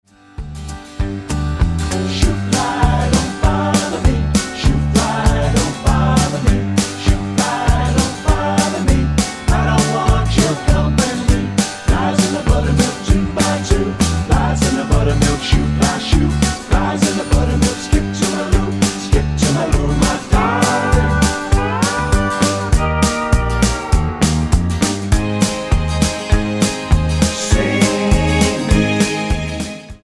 Tonart:G-Ab-A mit Chor